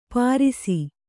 ♪ pārisi